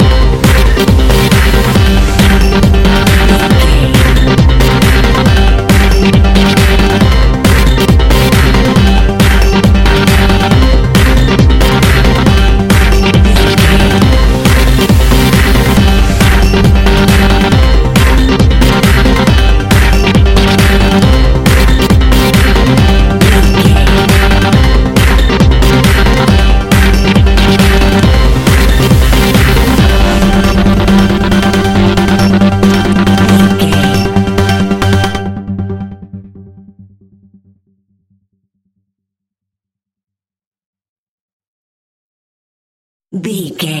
Aeolian/Minor
Fast
energetic
uplifting
hypnotic
drum machine
piano
synthesiser
electronic
uptempo
synth leads
synth bass